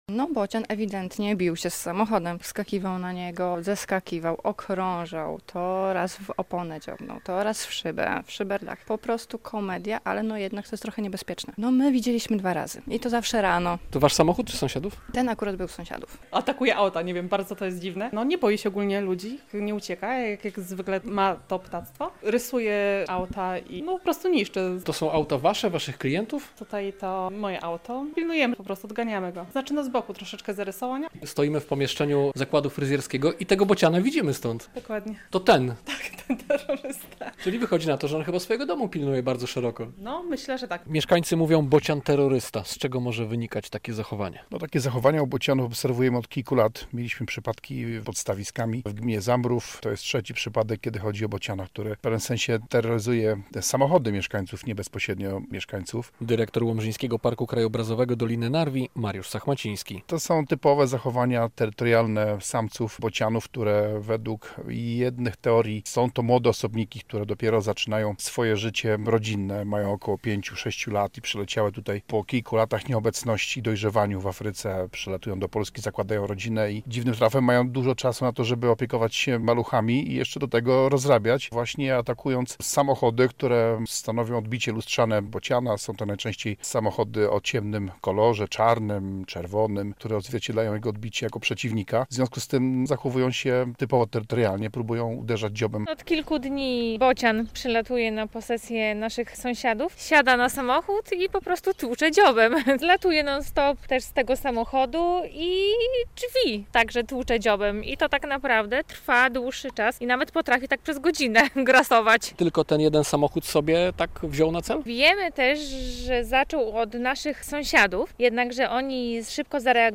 Piątnica: Bocian atakuje samochody - relacja
- mówią mieszkańcy Piątnicy.